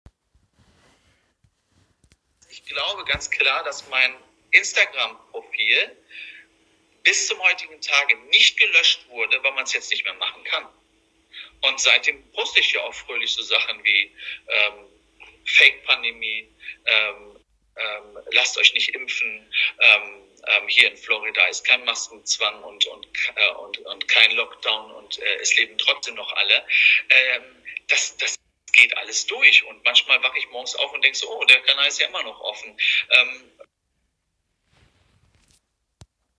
Im Gespräch mit Eva Herman sagte er Folgendes:
Hier ist zum Beweis die Tonaufnahme seiner Äußerungen: